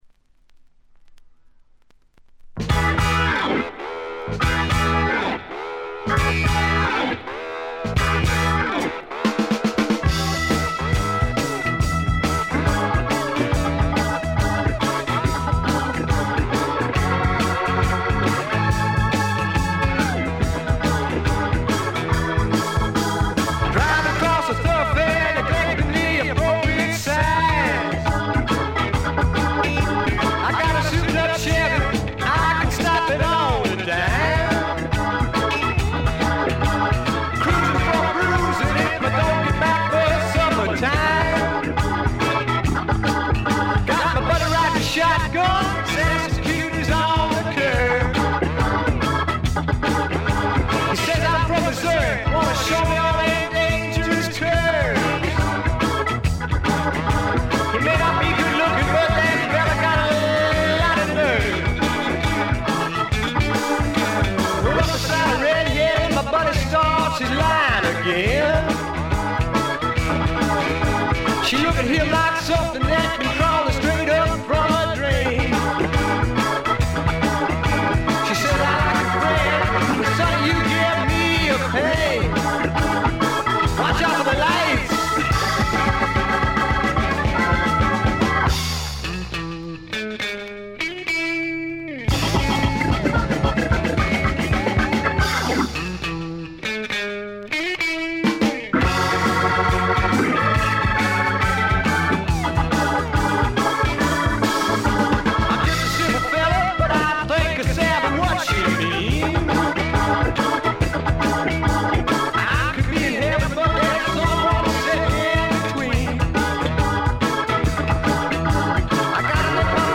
ところどころでチリプチ。
カントリー風味、オールド・ロックンロールを元にスワンプというには軽い、まさに小粋なパブロックを展開しています。
試聴曲は現品からの取り込み音源です。